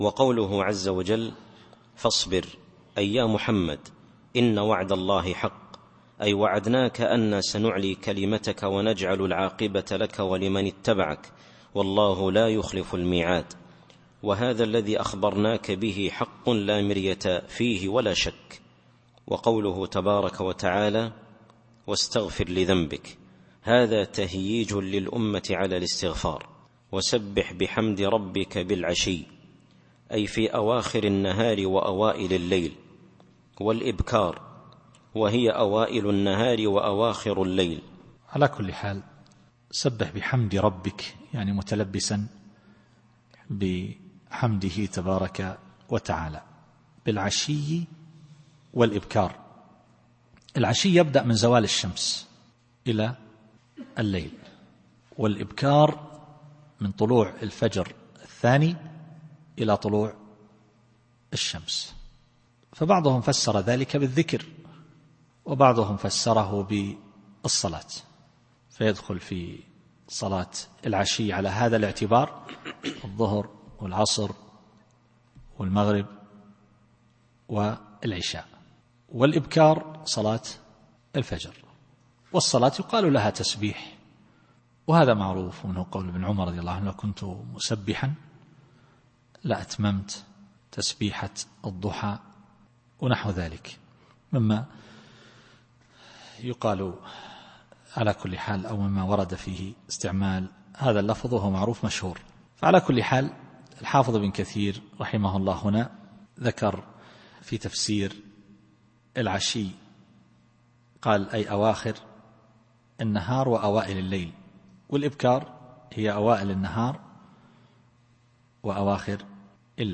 التفسير الصوتي [غافر / 55]